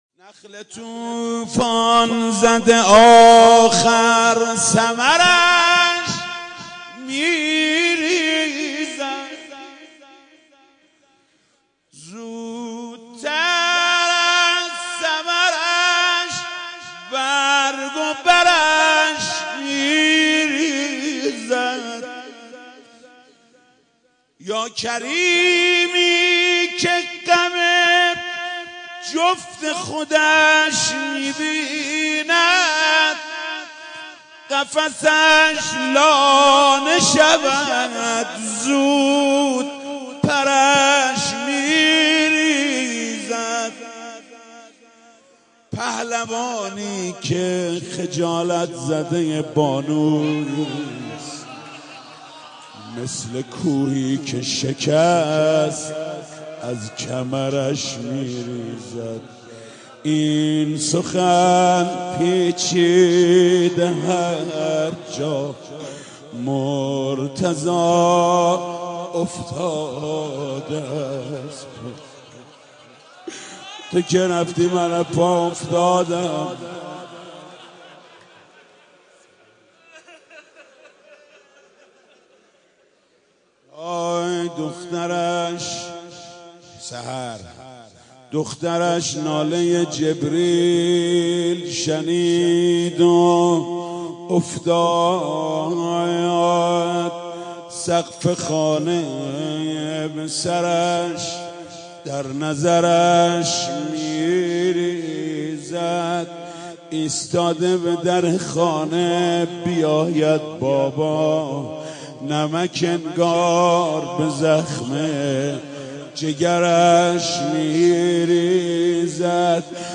متن روضه حضرت علی علیه السلام با صدای حاج محمود کریمی -( نخل طوفان زده آخر ثمرش میریزد )